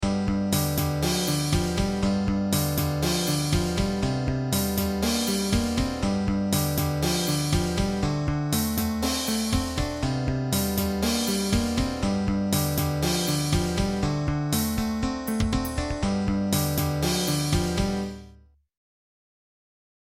Blues Exercises > Bluse in G